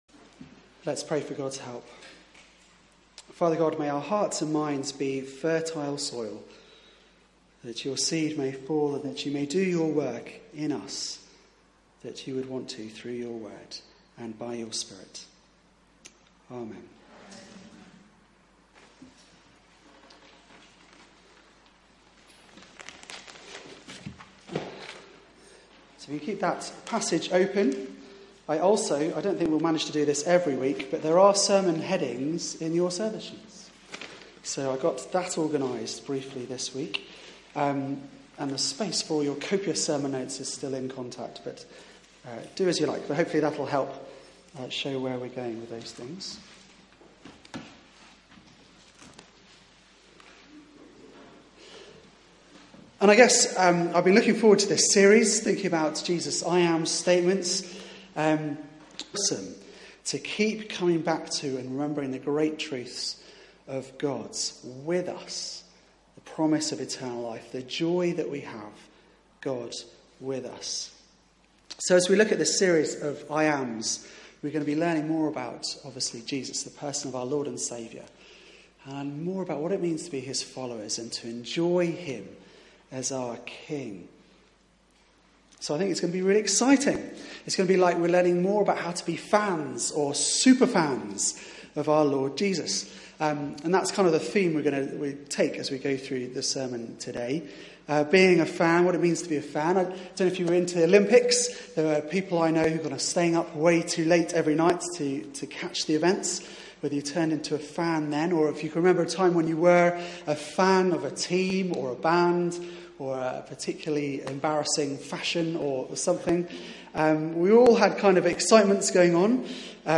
Media for 4pm Service on Sun 04th Sep 2016 16:00 Speaker
Theme: The Great I am Sermon